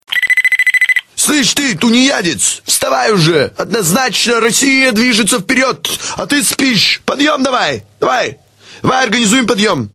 Главная » Рингтоны » Рингтоны на будильник